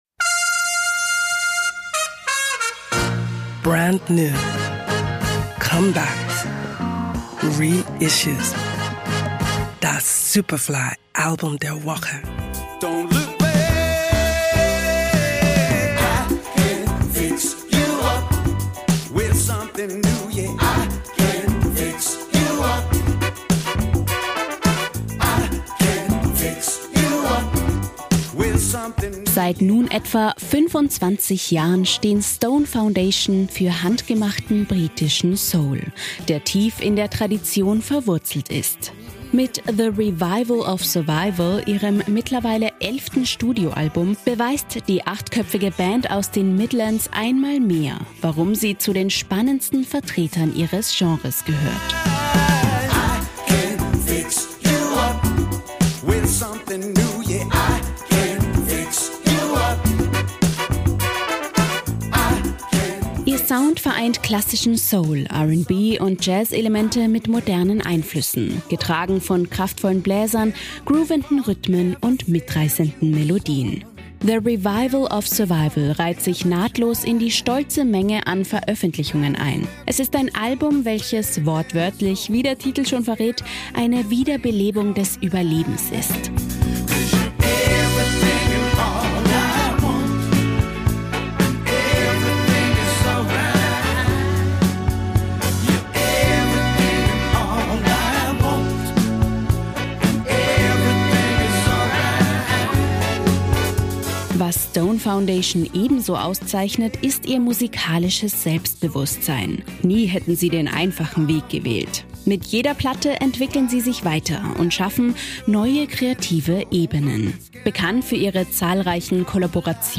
handgemachten britischen Soul
beweist die achtköpfige Band aus den Midlands einmal mehr